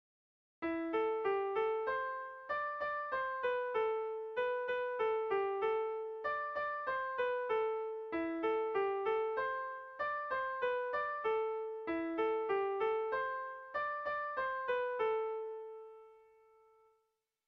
Neurrian ez dabil zuzen zuzen.
A1A2